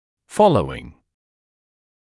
[‘fɔləuɪŋ][‘фолэуин]после, вслед за; последующий, следующий